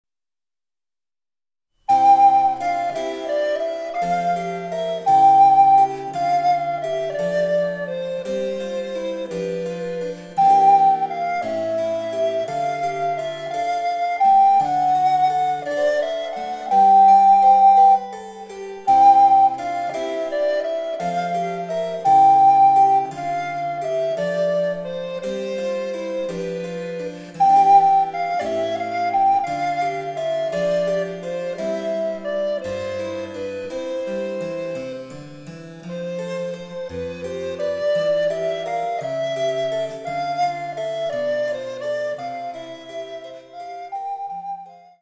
リコーダー演奏